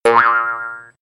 دانلود صدای در رفتن فنر از ساعد نیوز با لینک مستقیم و کیفیت بالا
جلوه های صوتی
برچسب: دانلود آهنگ های افکت صوتی اشیاء